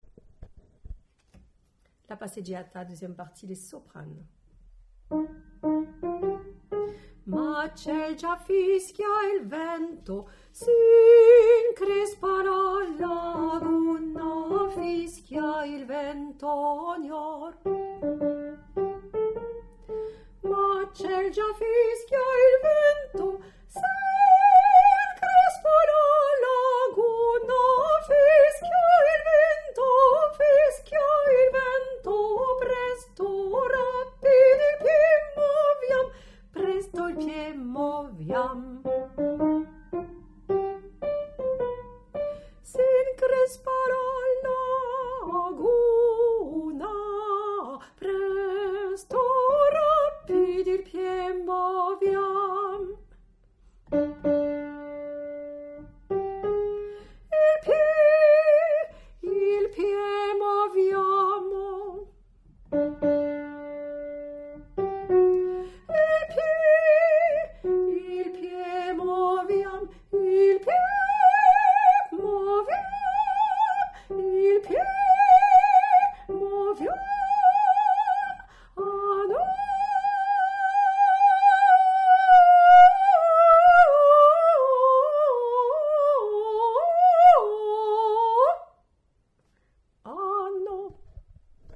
passeggiata2_Soprano.mp3